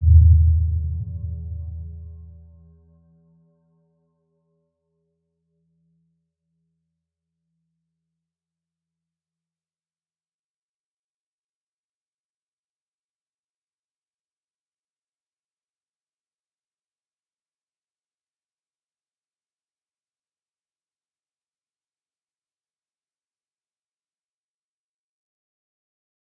Round-Bell-E2-p.wav